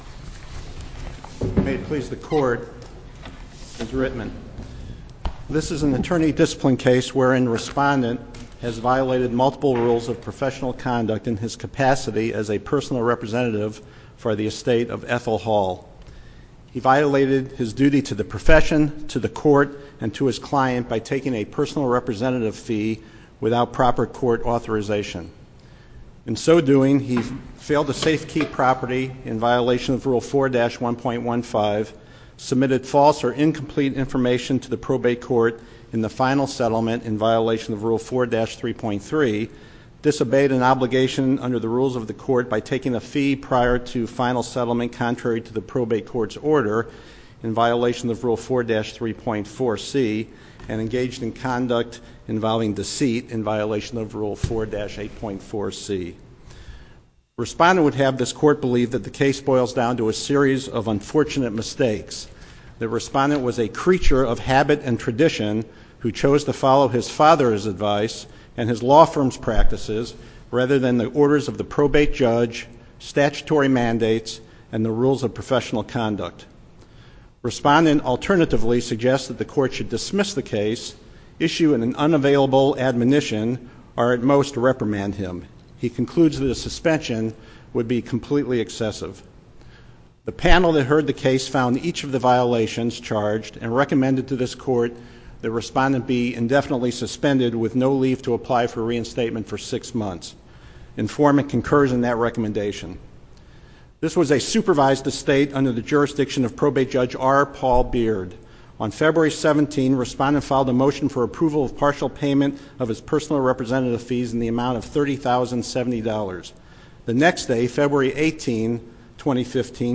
link to MP3 audio file of oral arguments in SC97018
SUPREME COURT OF MISSOURI